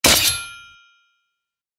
ダンジョン・フィールド効果音 | 著作権フリーの効果音
トラップ_刃_針.mp3